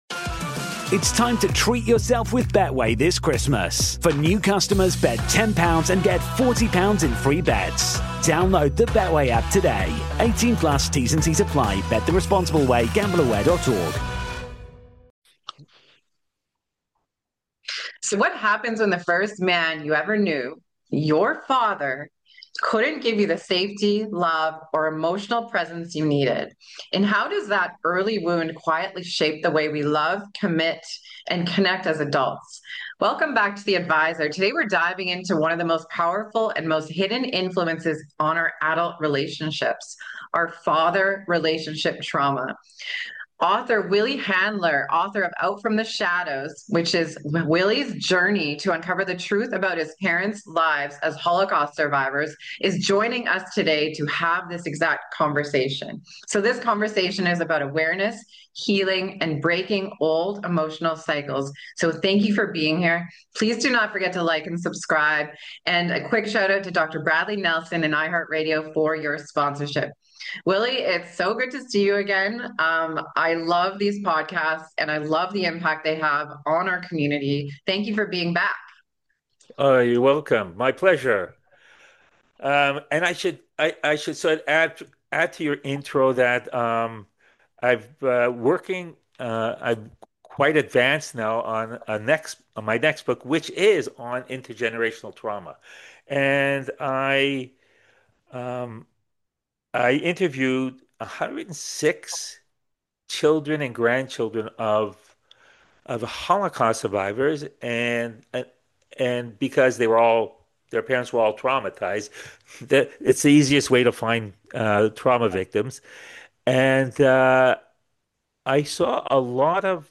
You’ll hear a compassionate, practical conversation about what’s really happening underneath “commitment issues,” and how both men and women can respond with understanding instead of blame.